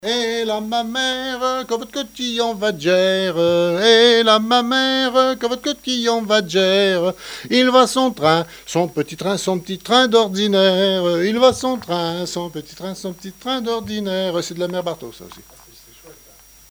Mémoires et Patrimoines vivants - RaddO est une base de données d'archives iconographiques et sonores.
Couplets à danser
danse : branle : courante, maraîchine
Pièce musicale inédite